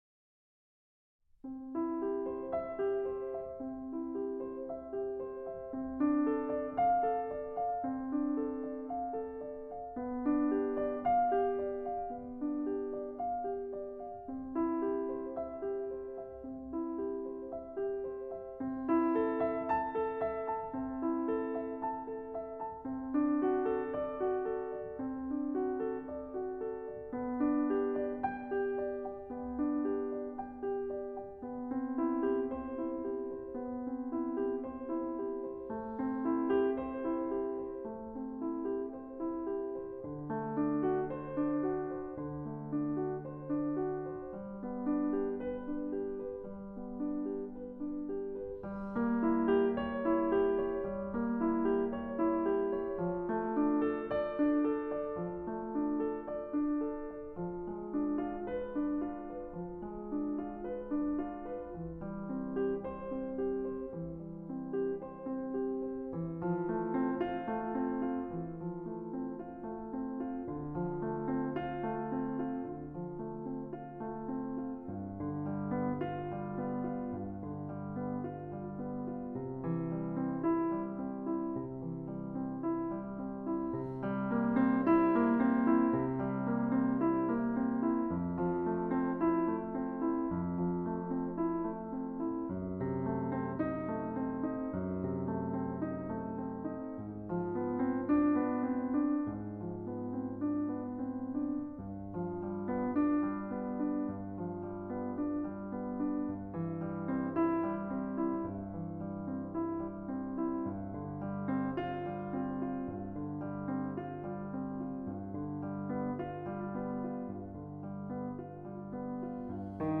全体を通して安定のテンポで、揺れが入り込む余地はほとんどありません。
【テンポがブレない古典派曲の例】
カチッカチッと表紙を刻む拍の音が今にも聞こえてきそうな安定のテンポやリズムで、規則正しく演奏されています。